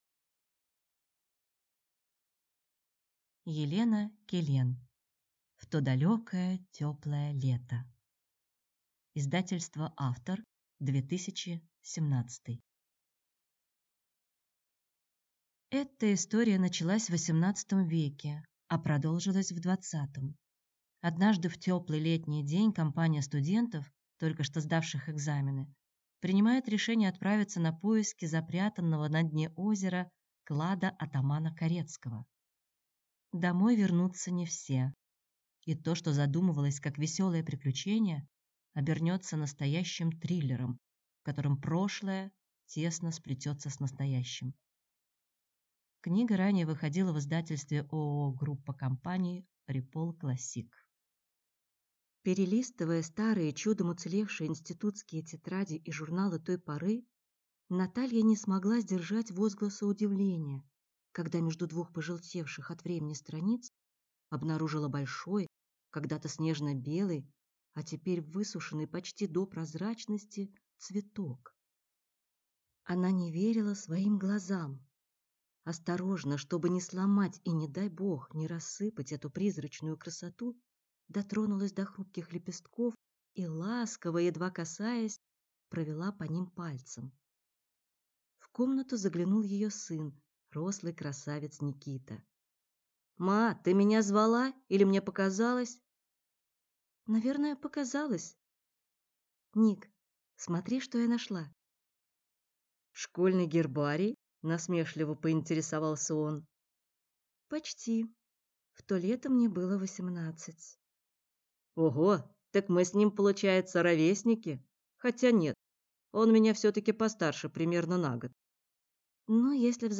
Аудиокнига В то далекое теплое лето | Библиотека аудиокниг